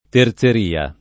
terzeria [ ter Z er & a ]